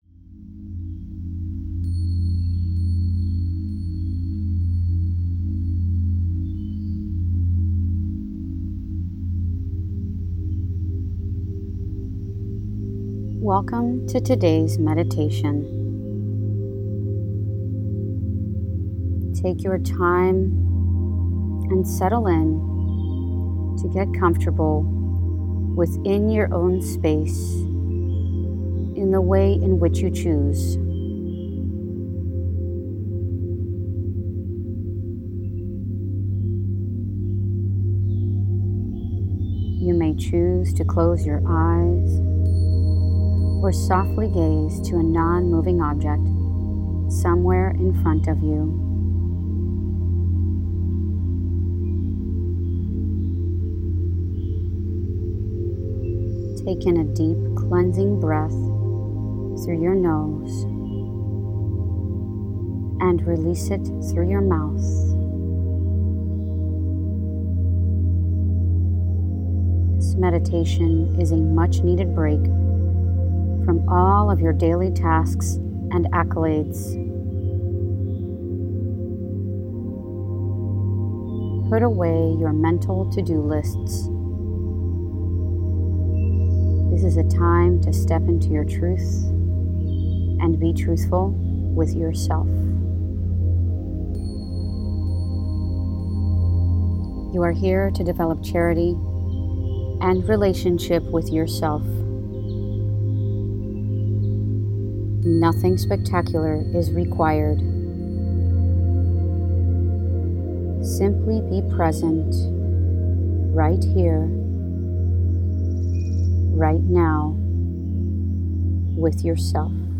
Breathe – meditate. Take just under 9 minutes to drop in, destress, and breathe.
Meditation-3.m4a